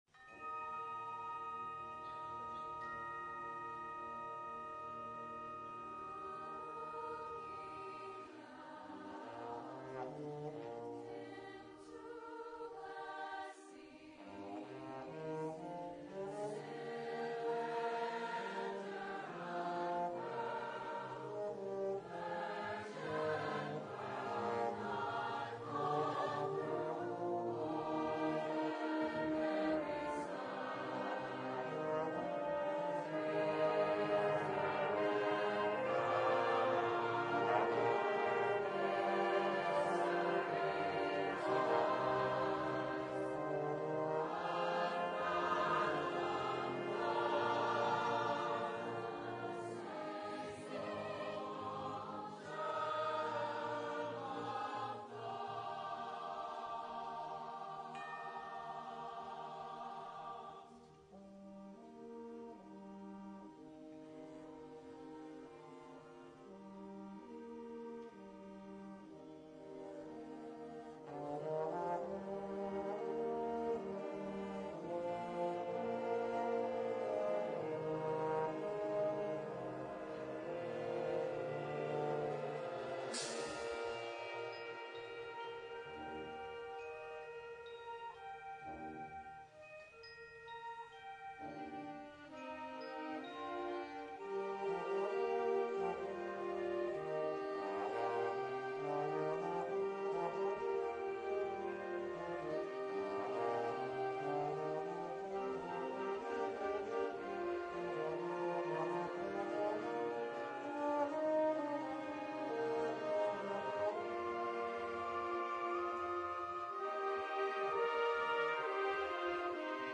Concerto for Bass Trombone, Symphonic Band & Choir
2nd Performance
Live ( Movements II & III)